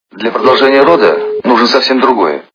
» Звуки » Из фильмов и телепередач » к/ф Тот самый Мюнхгаузен - Для продолжения рода нужно совсем другое
При прослушивании к/ф Тот самый Мюнхгаузен - Для продолжения рода нужно совсем другое качество понижено и присутствуют гудки.